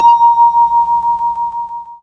- Dings now play when revealing answer choices. 2025-06-01 20:34:22 -04:00 22 KiB Raw History
Ding - A#5.ogg